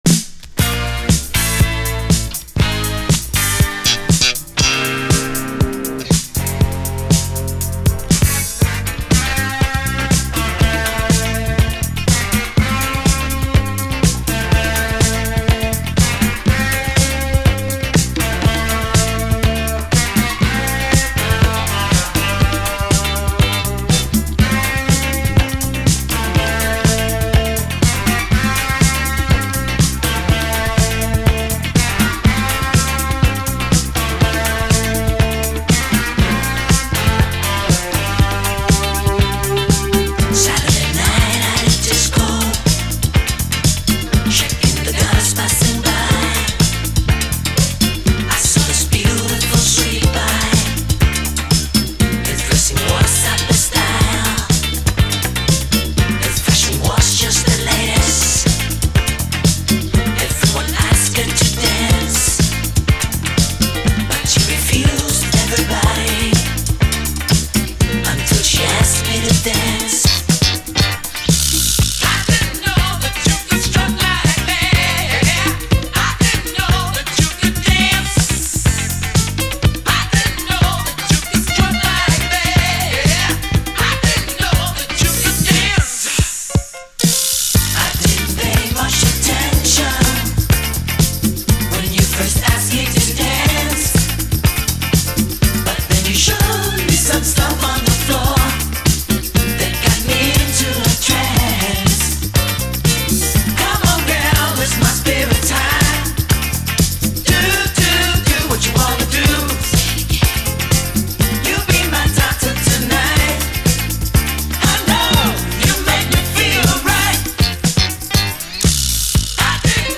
SOUL, 70's～ SOUL, DISCO
マイアミ産トロピカル・ディスコ・ファンク！
A面アタマの無音部溝にジリッとノイズあり